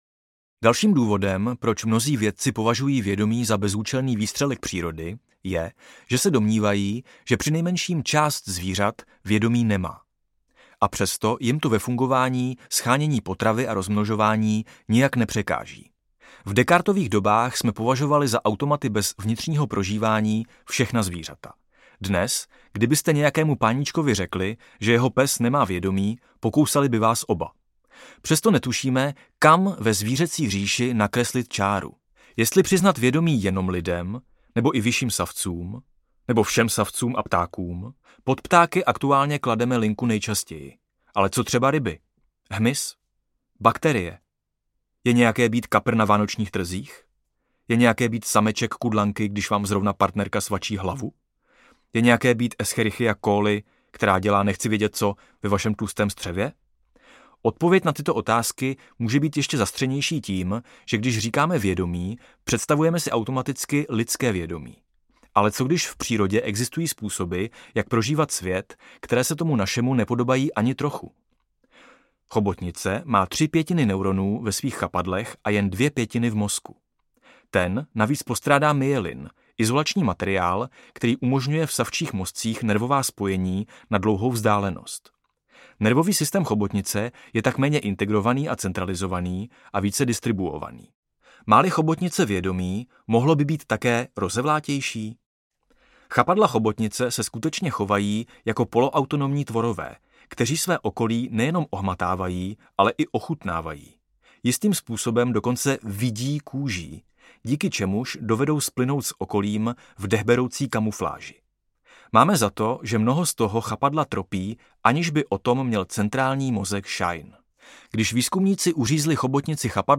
Jak se stát nikým: Probuzení pro skeptiky audiokniha
Ukázka z knihy
Vyrobilo studio Soundguru.